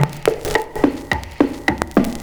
106PERCS02.wav